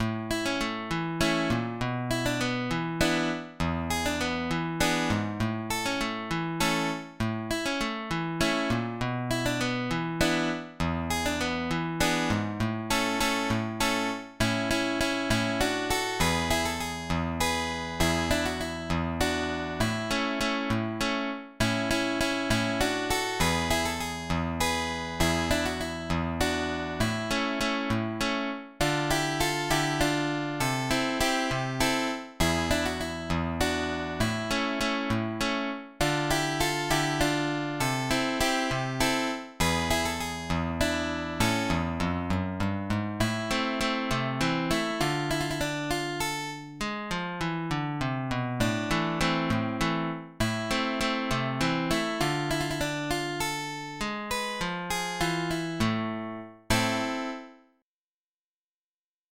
Zamacueca***